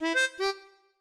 melodica_ec1g.ogg